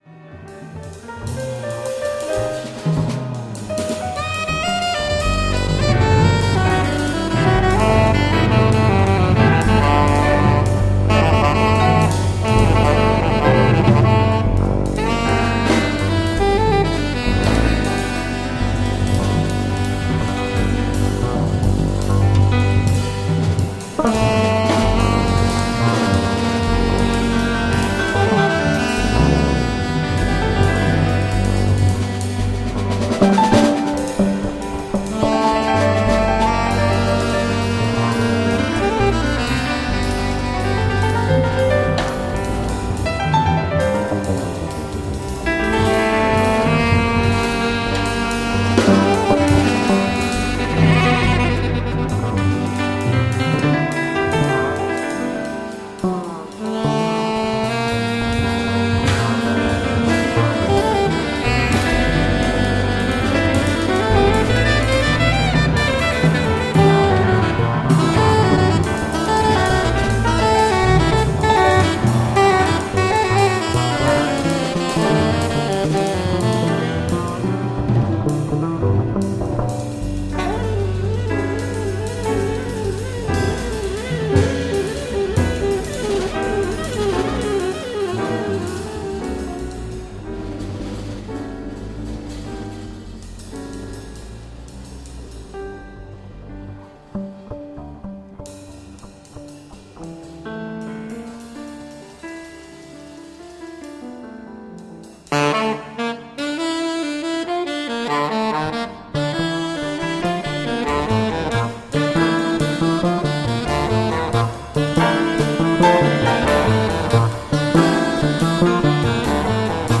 Recorded live at the Vancouver Jazz Festival, Canada,